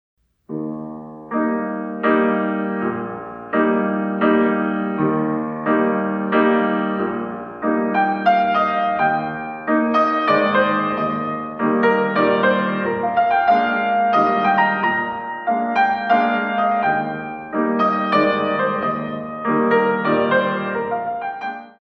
In 3
32 Counts